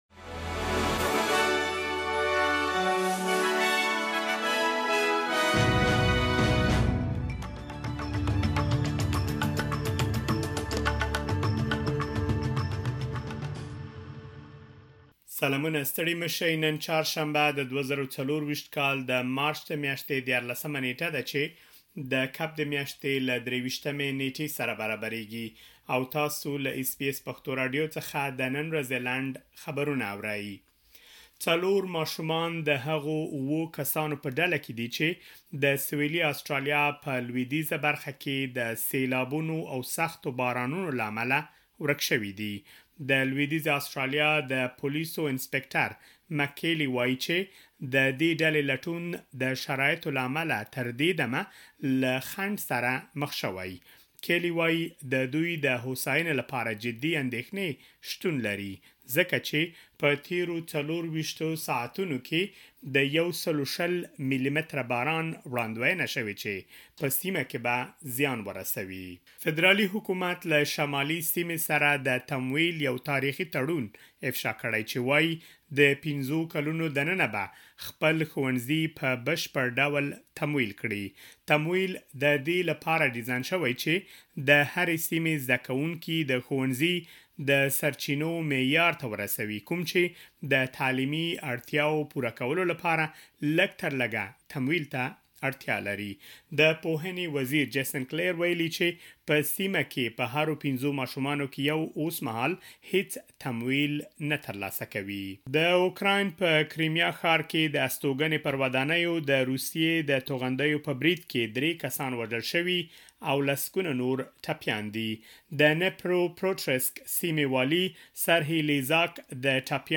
د اس بي اس پښتو راډیو د نن ورځې لنډ خبرونه|۱۳ مارچ ۲۰۲۴